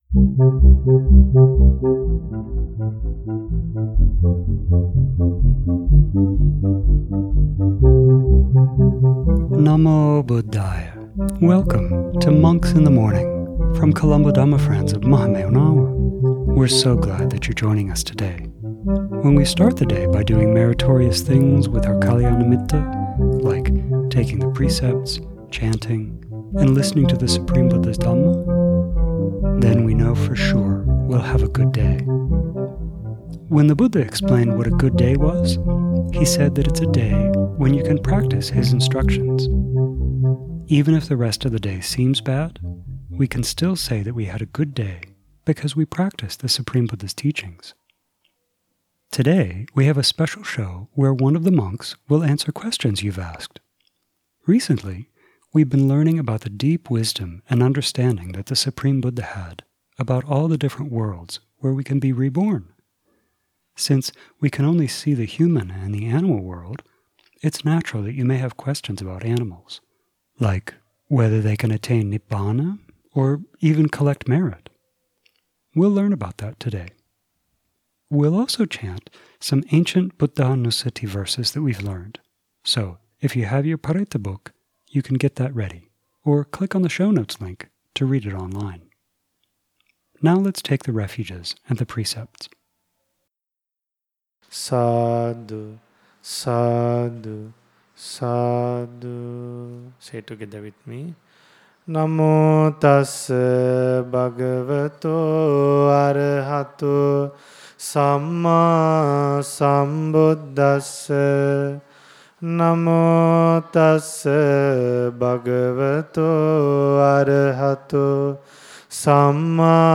Today we have a special show where one of the monks will answer questions you have asked.